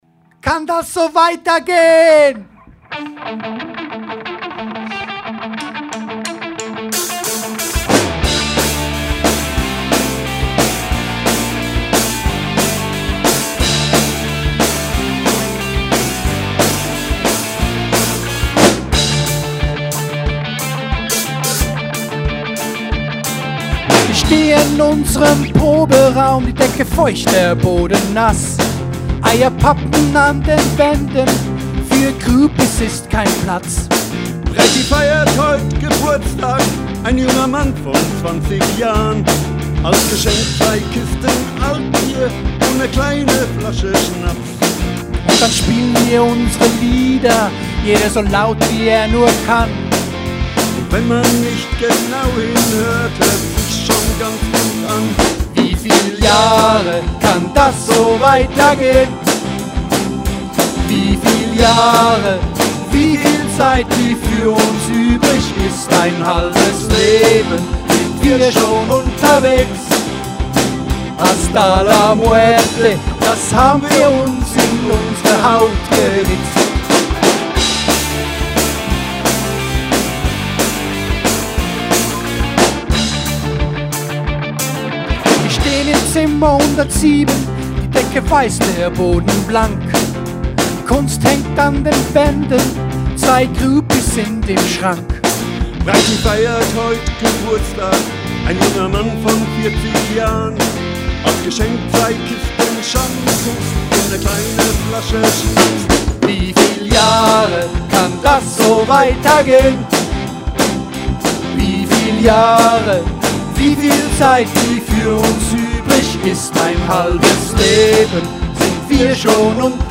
Noch ein cooler Punksong